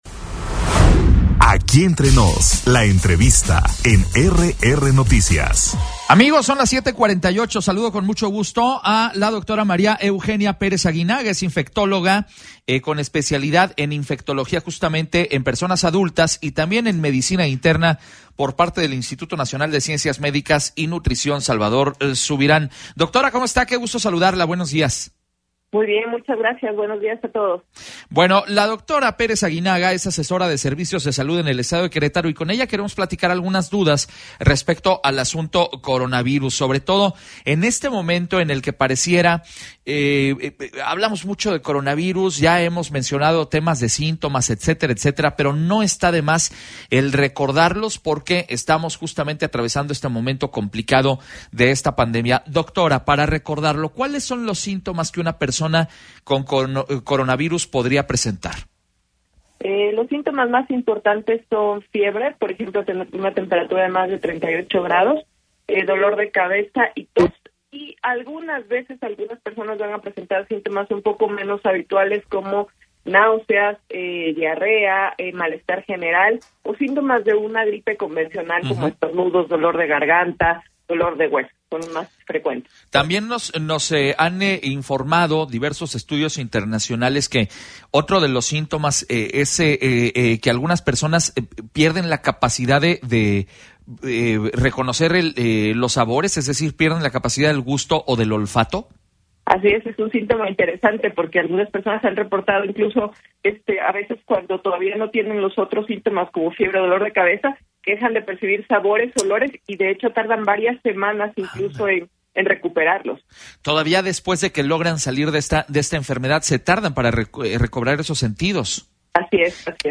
EntrevistasMultimediaPodcast